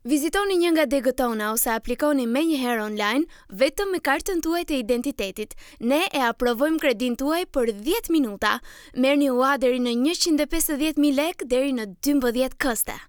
Our native speakers of Albanian:
Albanian_18 Female
Albanian_18 Female.mp3